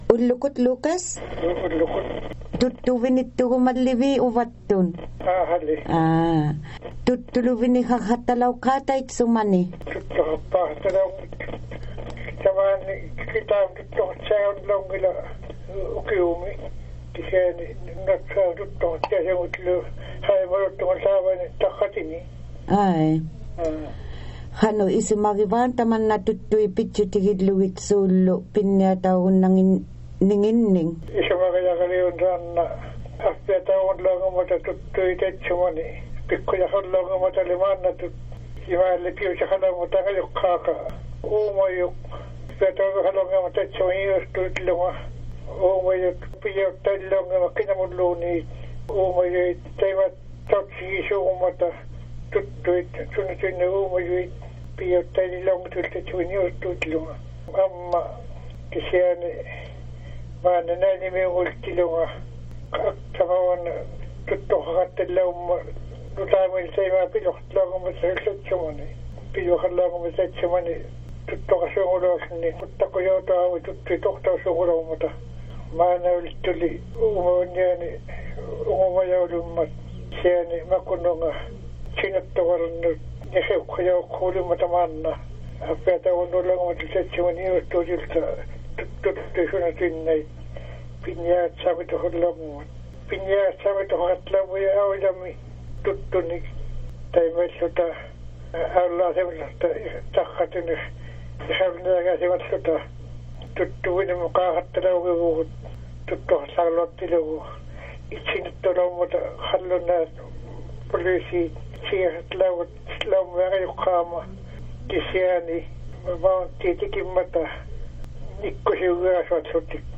We all enjoy hearing stories from Elders.